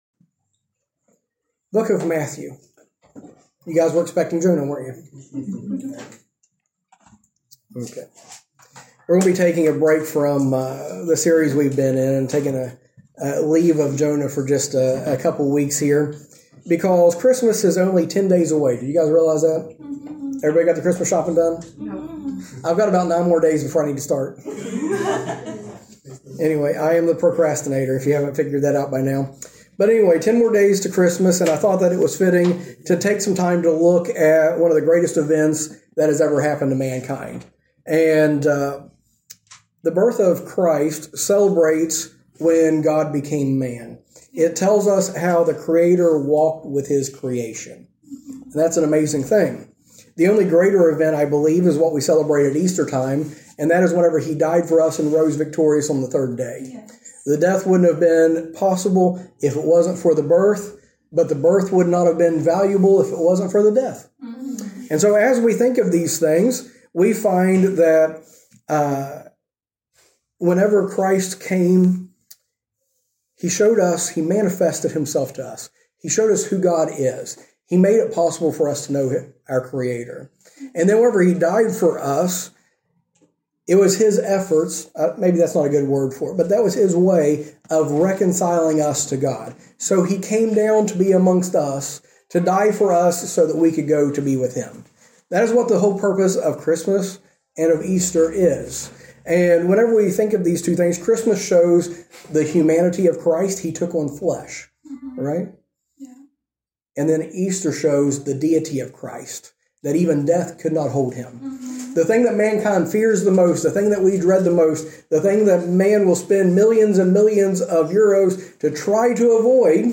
A message from the series "Series Breaks."